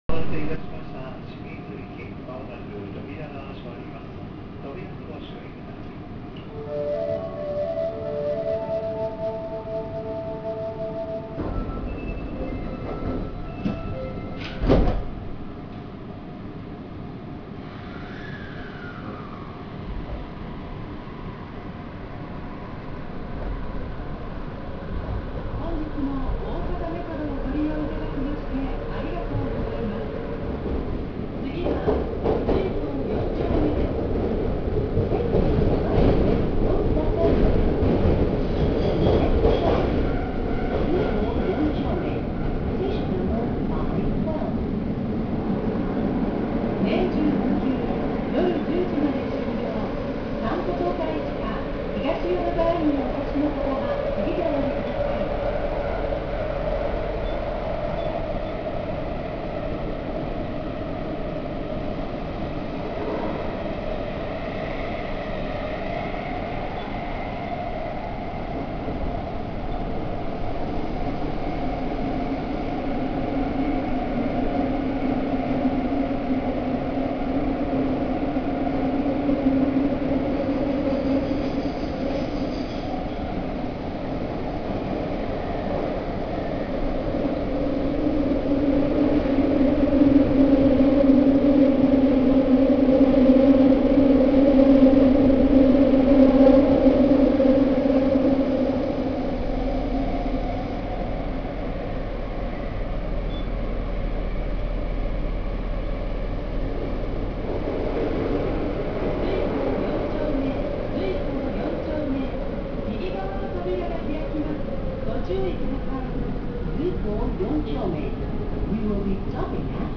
・80系走行音
【今里筋線】井高野→瑞光四丁目（2分15秒：733KB）
日立IGBTを採用していますが、70系更新車のそれとはモーター音は異なります。ドアチャイムが設置されているのも70系との違いと言えそうです。